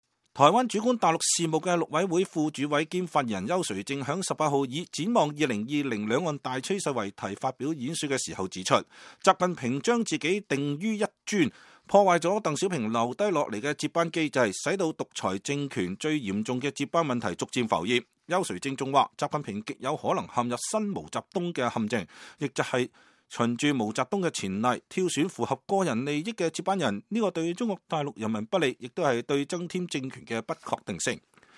台灣主管大陸事務的陸委會副主委兼發言人邱垂正18日以“展望2020兩岸大趨勢”為題發表演說時指出，習近平將自己定於一尊，破壞了鄧小平留下的接班機制，使得獨裁政權最嚴重的接班問題逐漸浮現。